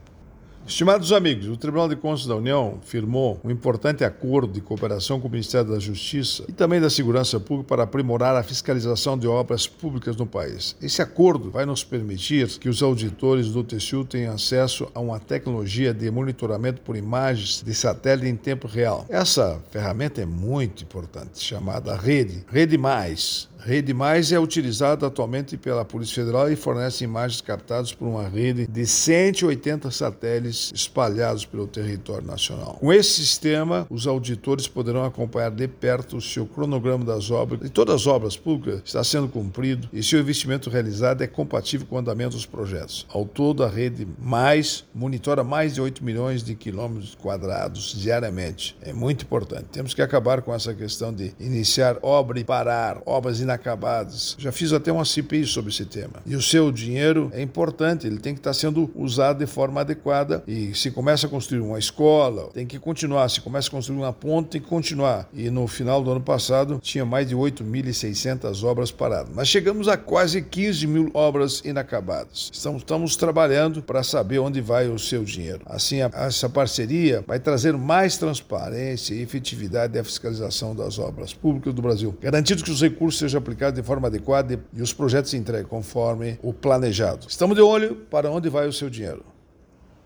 É o assunto do comentário desta quinta–feira (19/09/24) do ministro Augusto Nardes (TCU), especialmente para OgazeteitO.